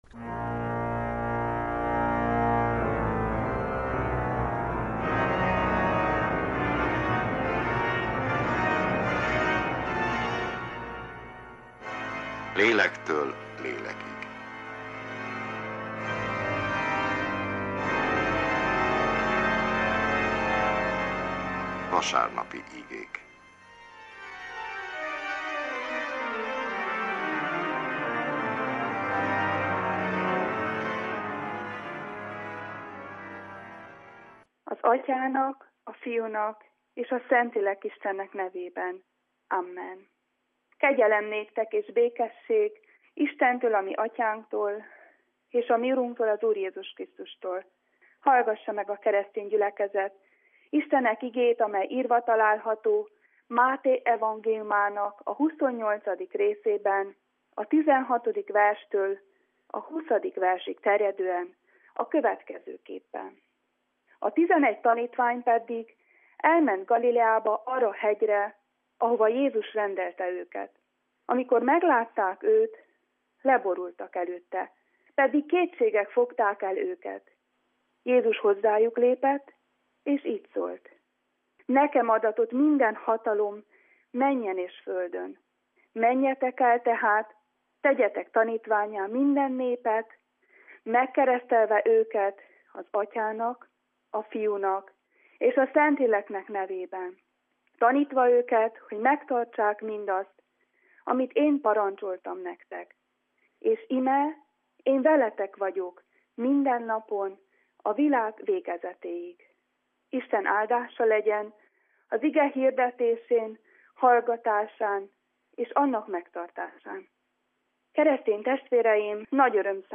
Evangélikus igehirdetés, július 24.
Egyházi műsor